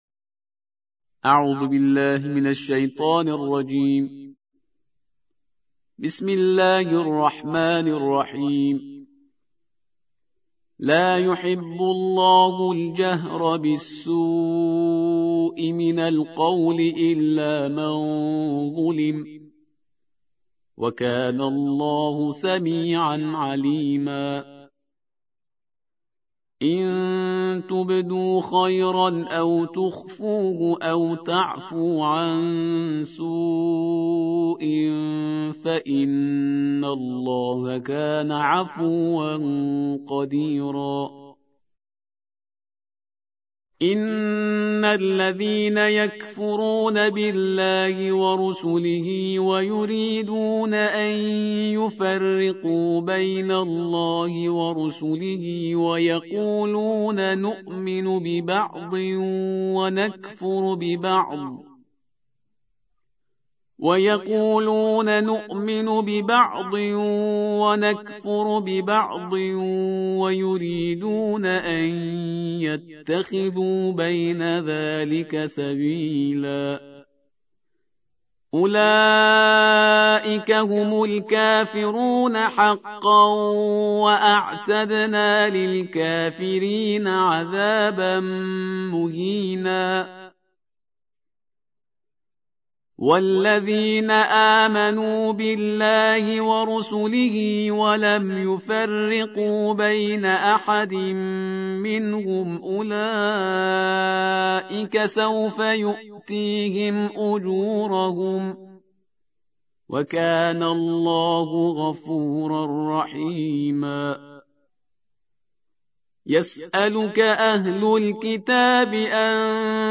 تحميل : الجزء السادس / القارئ شهريار برهيزكار / القرآن الكريم / موقع يا حسين